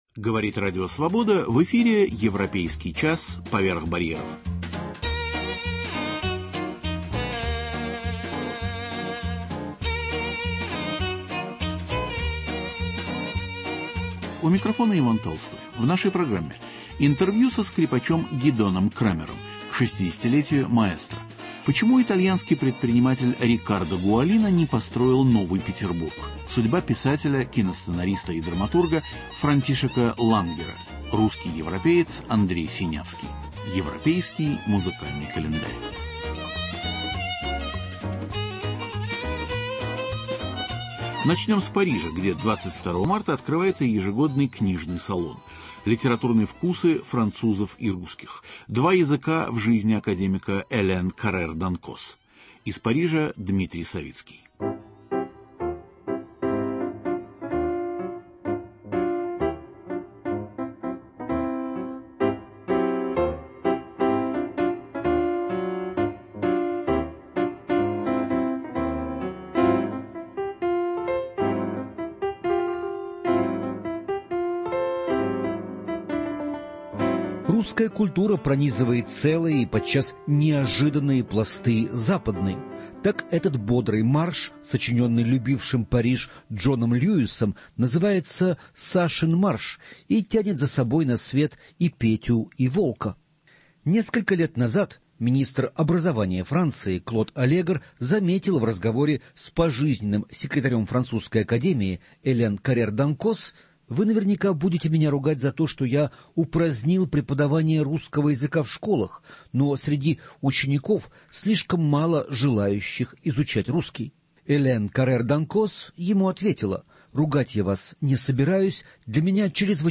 Интервью со скрипачом Гидоном Кремером: к 60-летию маэстро. Почему итальянский архитектор начала ХХ века Рикардо Гуалино не построил Новый Петербург?